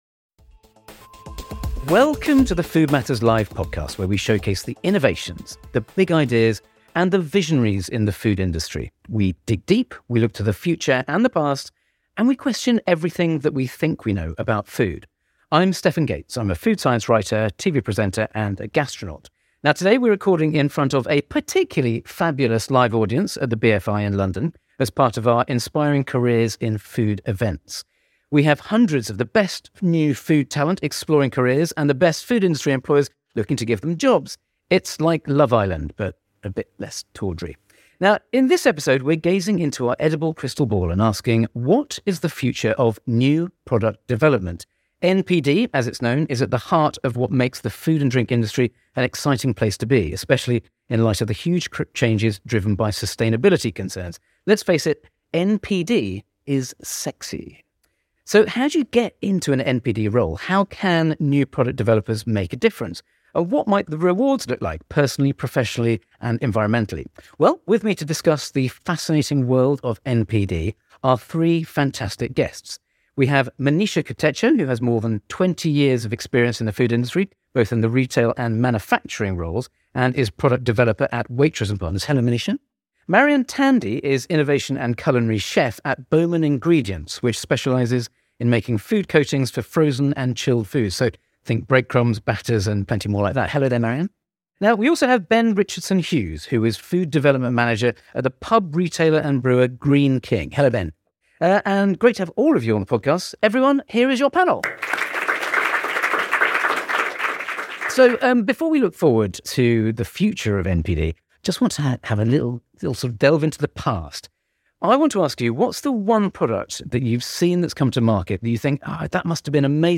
This episode was recorded in front of a live audience at the BFI in London as part of our Inspiring Careers in Food events .